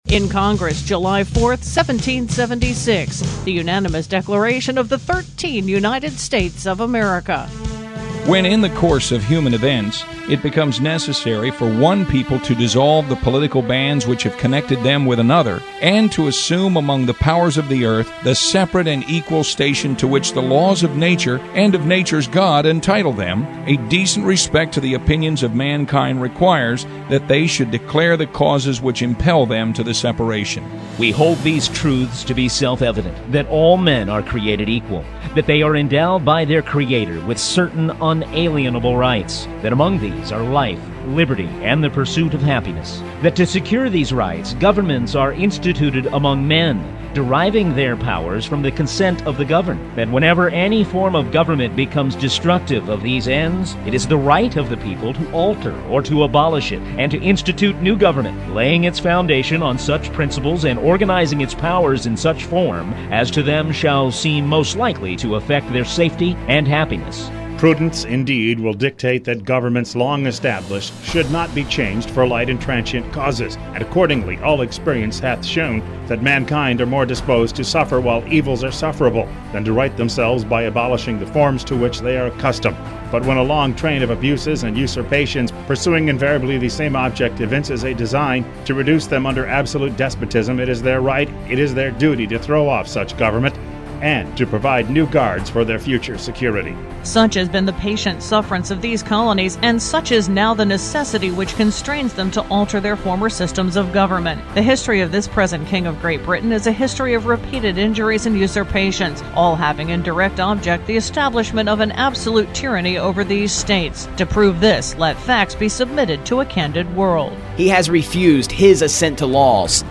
Talk of Delmarva 45th Anniversary Reading of the Declaration of Independence